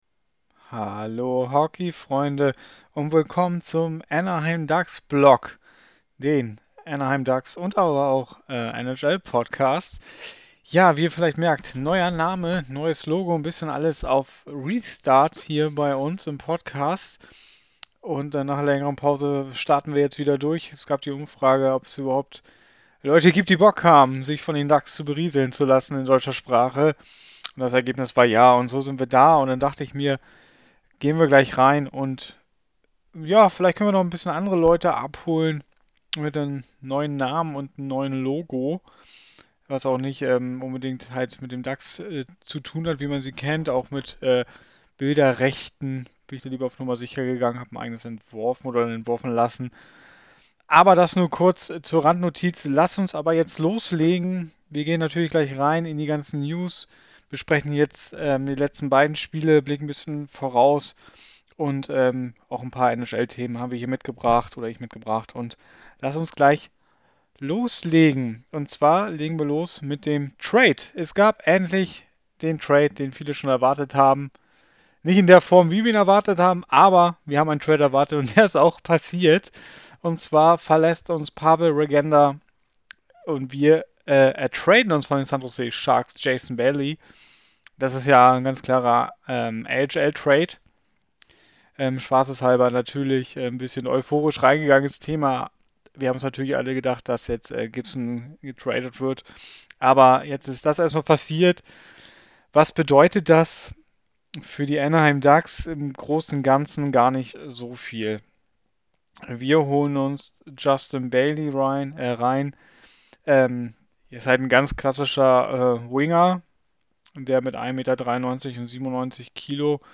Fantalk